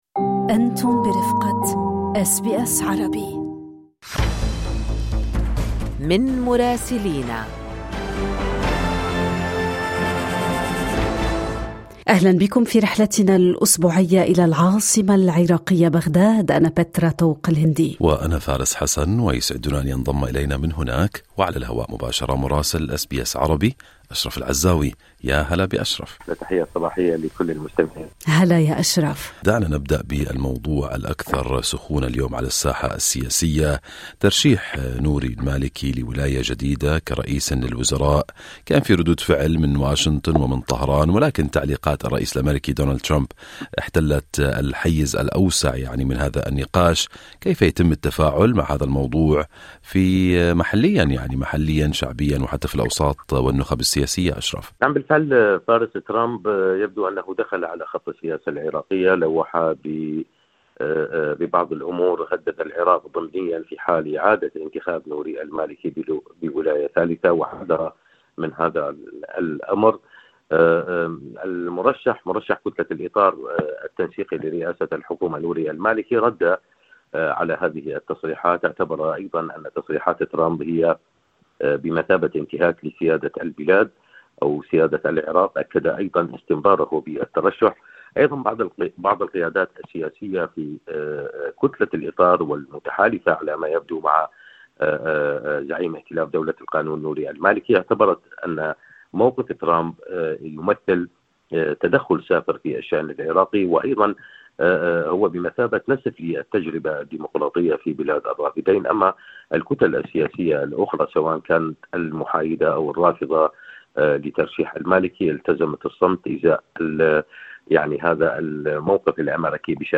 تقرير مراسلنا في بغداد يرصد مشهداً سياسياً وأمنياً بالغ التعقيد، تتداخل فيه الاستحقاقات الدستورية مع الضغوط الخارجية والتوترات الإقليمية. ففي المحور الأول، أثار ترشيح نوري المالكي لولاية جديدة ردود فعل واسعة، لا سيما بعد تصريحات الرئيس الأميركي دونالد ترامب التي عُدّت تدخلاً في الشأن العراقي.